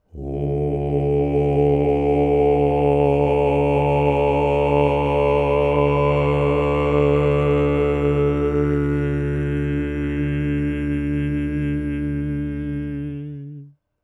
III. Bass Convolved with Self (as used in Temple)